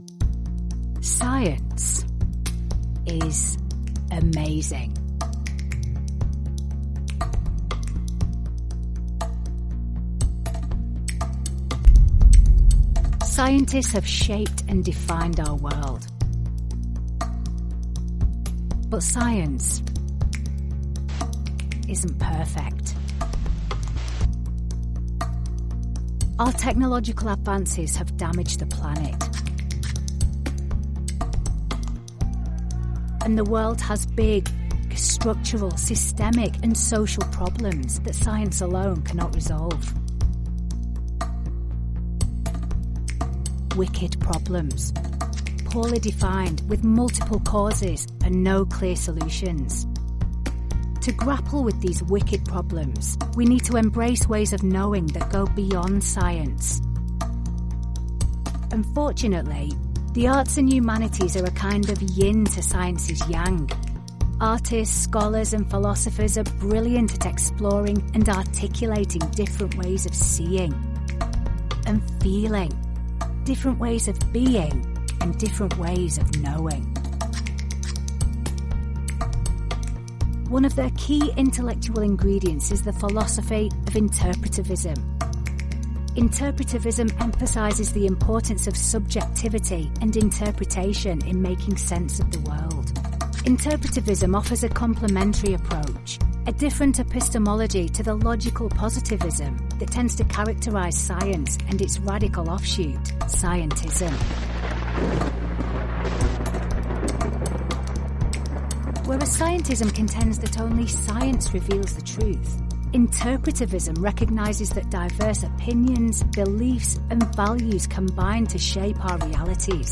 Fast, reliable, and naturally conversational, she delivers professional voice over that connects and compels.
Corporate Videos
* Purpose built, isolated, acoustically treated sound booth
* Rode NT1-A Mic & pop shield